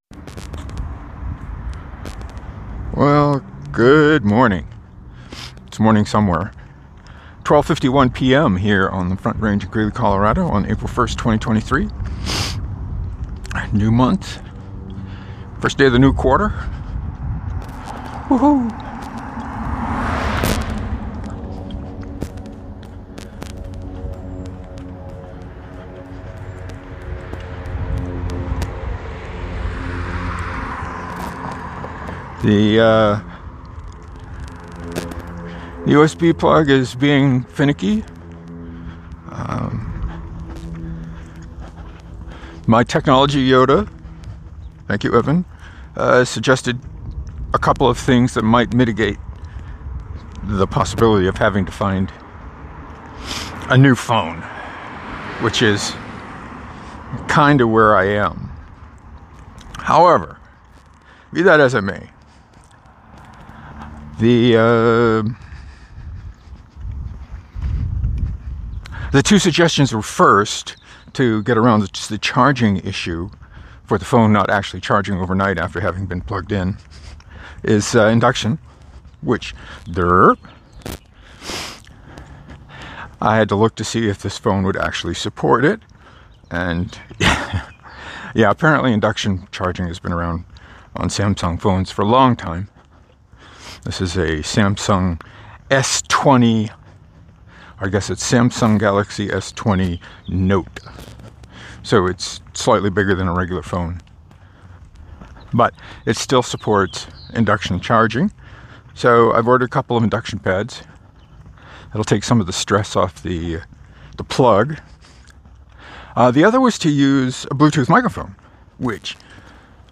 I talked a lot about the problems I’m having with the USB plug on this phone. The test of my LG HBS800s actually worked pretty well when I got the file home and cleaned it up in Audacity. It sounds really dead because it only picked up the loudest street noises.